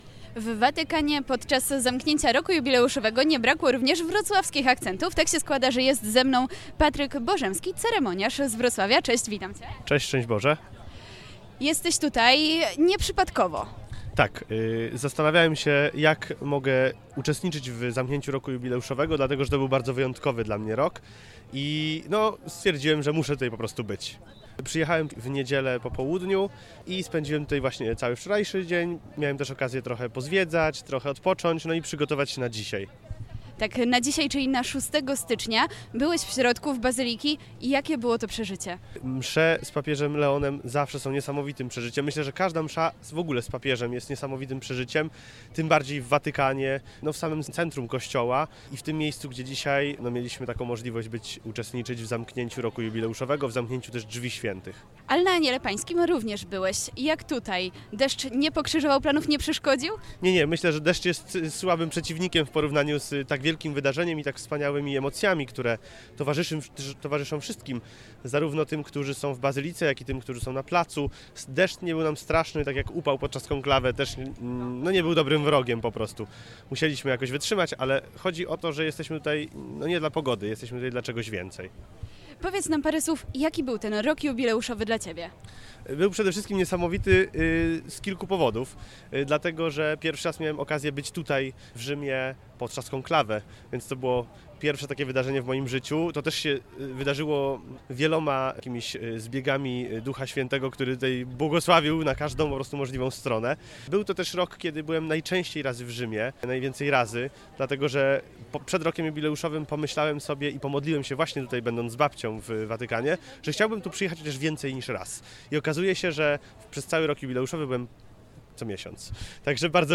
Rozmowy, które podczas naszego pobytu w Watykanie przeprowadziliśmy z uczestnikami zakończenia Roku Jubileuszowego pokazują, że był to czas głębokiego duchowego doświadczenia, odnowy serca i umocnienia wiary – zarówno osobistej, jak i wspólnotowej.